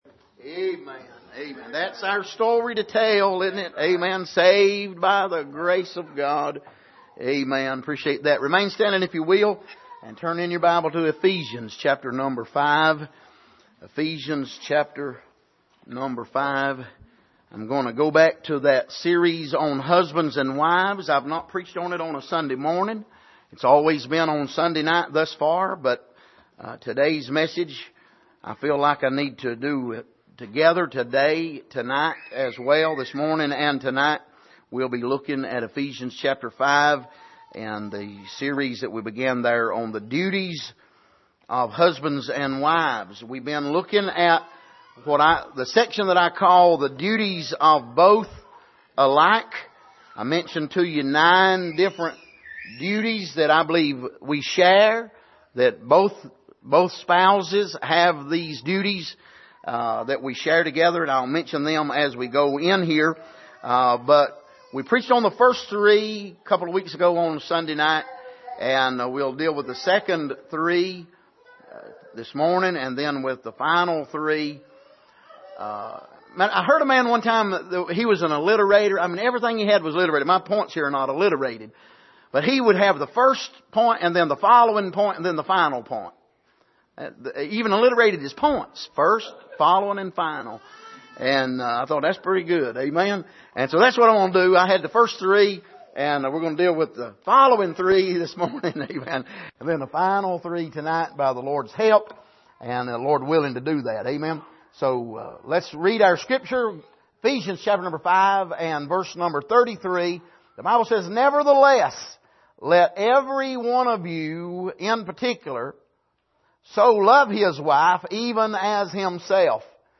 Passage: Ephesians 5:33 Service: Sunday Morning